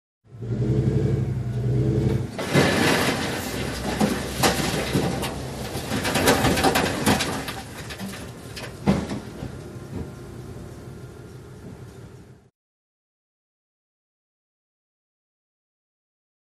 Dump Truck | Sneak On The Lot
Dump Truck; Backs Up And Dumps Coal.